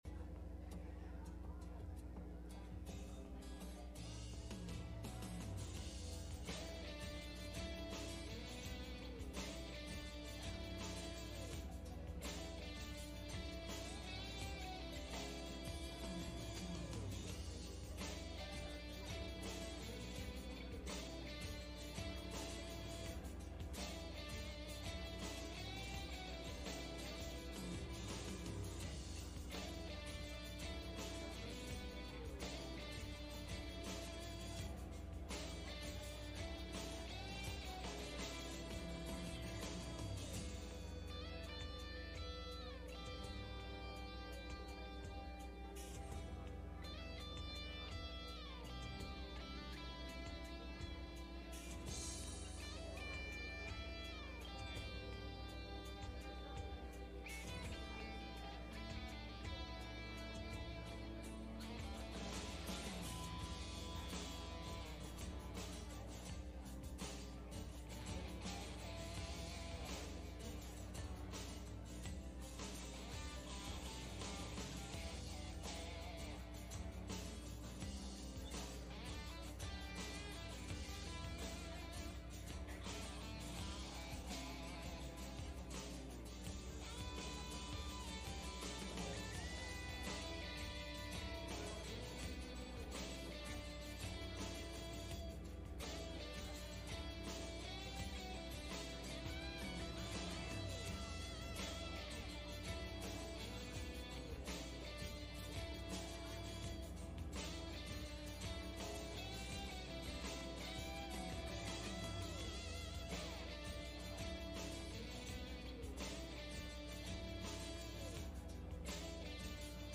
Message
Service Type: Sunday Morning